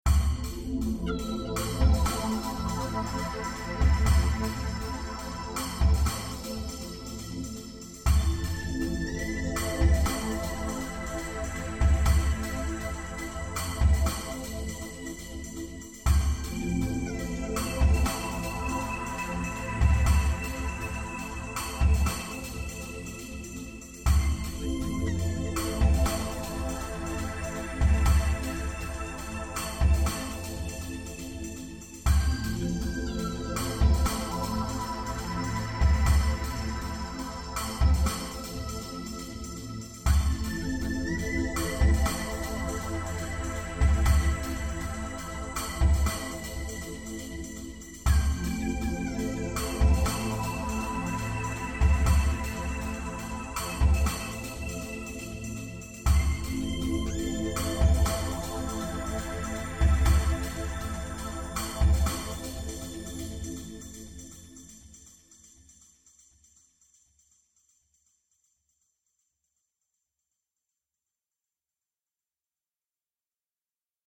Cold, icy, new agey, about 1:11Any feedback appreciated.